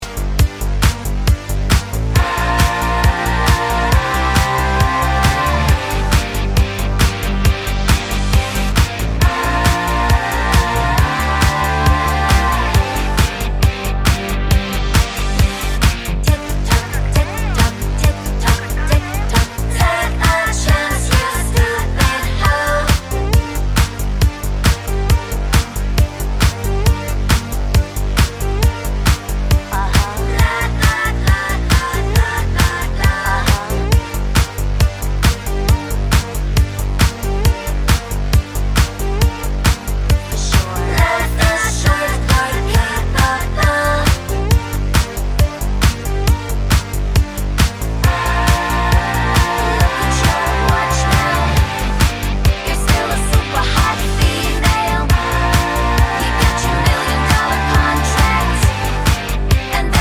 No Crowd SFX Pop (2000s) 3:36 Buy £1.50